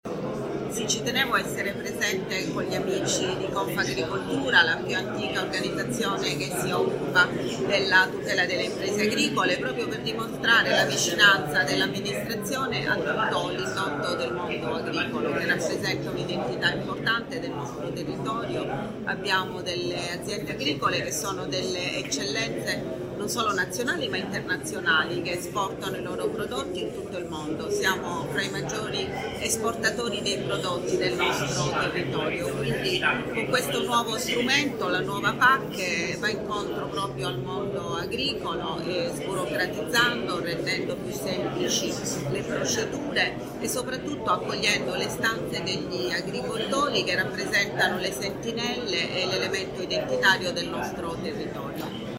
A testimoniare l’attenzione delle istituzioni locali verso il settore agricolo, era presente anche l’amministrazione comunale guidata dalla sindaca Matilde Celentano, che ha ribadito l’importanza di sostenere le imprese del territorio attraverso politiche mirate e un dialogo costante con gli operatori del settore: